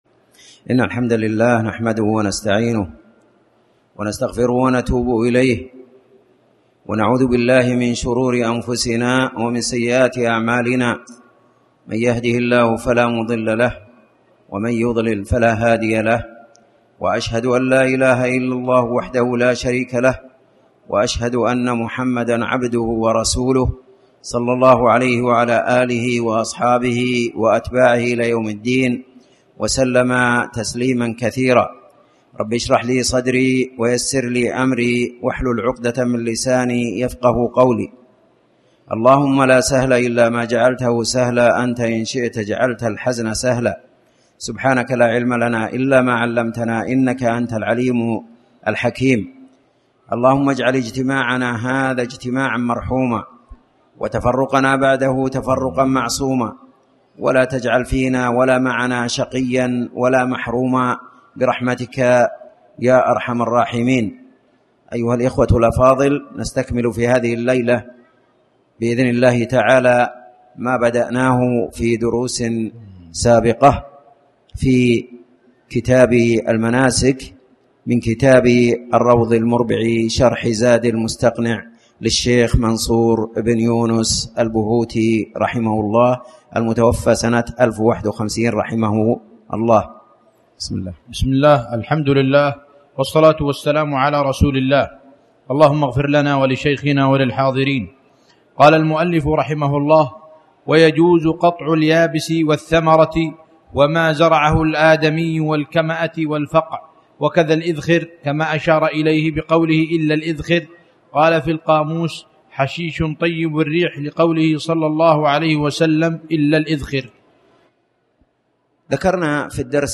تاريخ النشر ١٩ محرم ١٤٣٩ هـ المكان: المسجد الحرام الشيخ